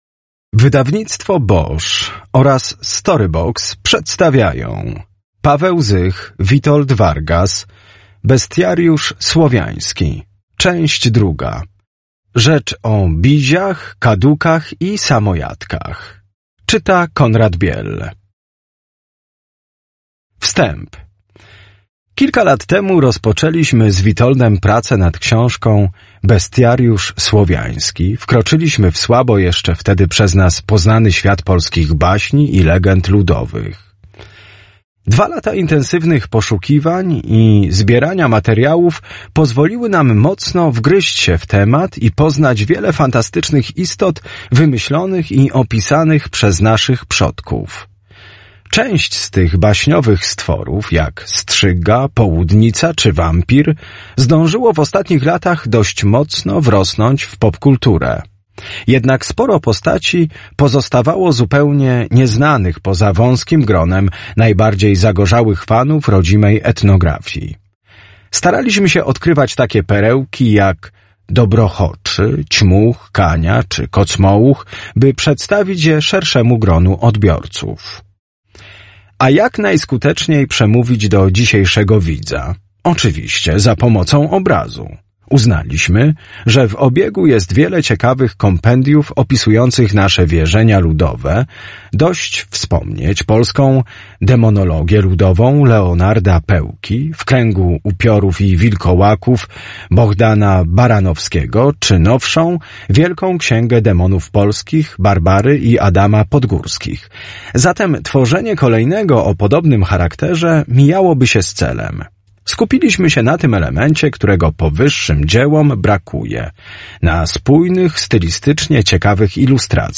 [Audiobook]